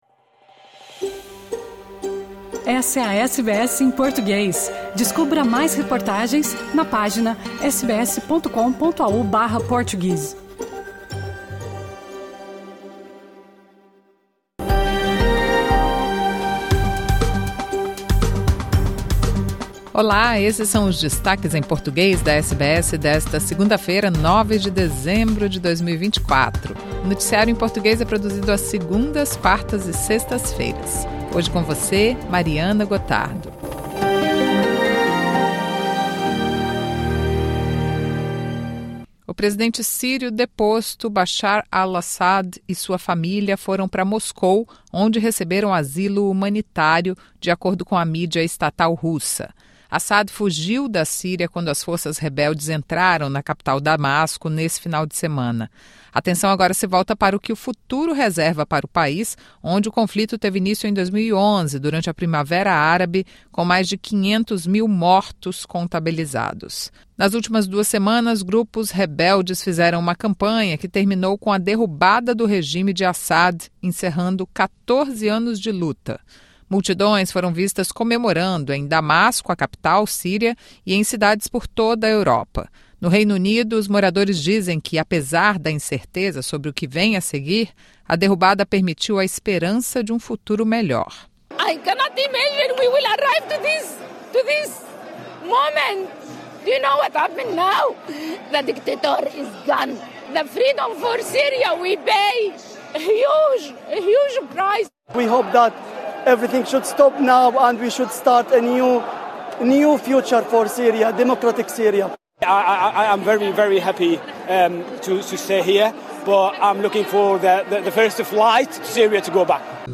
Presidente da Síria, Bashar al-Assad, recebe asilo humanitário em Moscou após rebeldes terem tomado Damasco e colocado um fim no seu governo. Presidente dos EUA e outros líderes mundiais comemoraram o ocorrido, assim como refugiados sírios em outros países. Essa e outras informações no noticiário desta segunda-feira da SBS em Português.